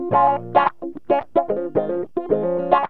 GTR 15A#M110.wav